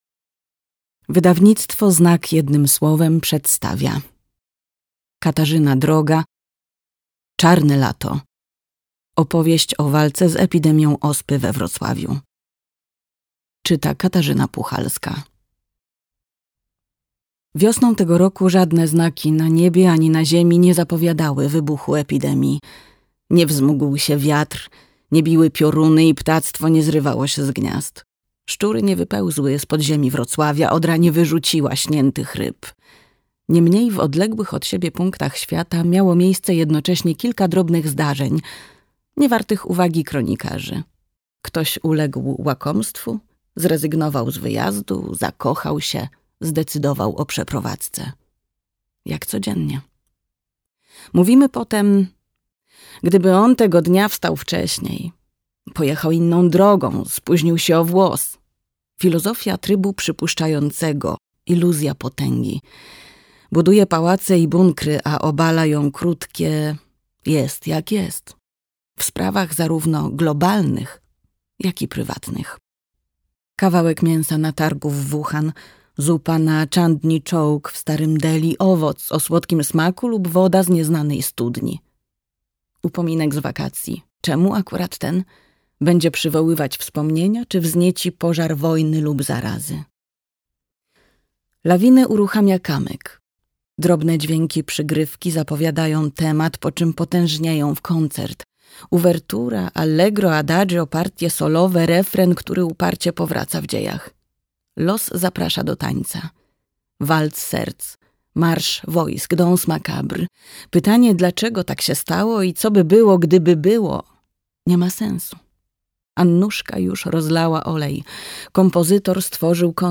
Opowieść o miłości i śmierci w czasie epidemii ospy we Wrocławiu - Katarzyna Droga - audiobook